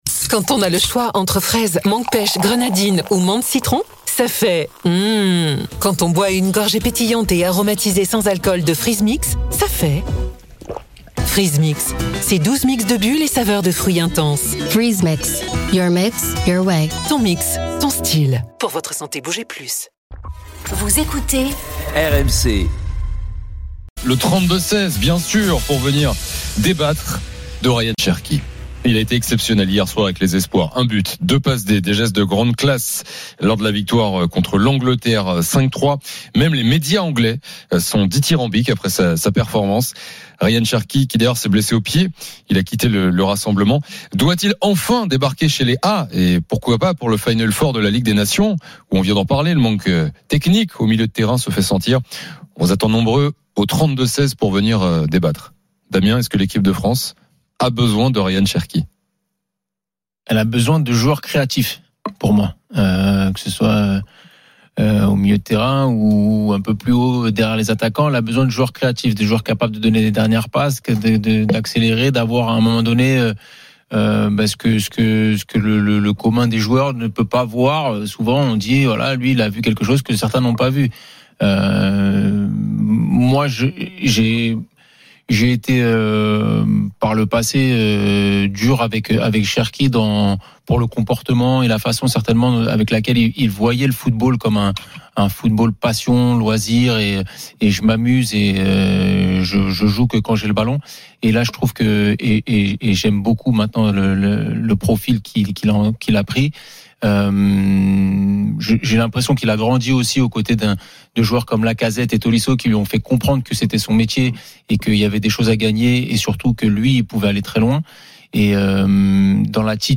les conférences de presse d’après-match et les débats animés entre supporters, experts de l’After et auditeurs RMC.
Chaque jour, écoutez le Best-of de l'Afterfoot, sur RMC la radio du Sport !